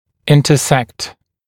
[ˌɪntə’sekt][ˌинтэ’сэкт]пересекать(ся), перекрещиваться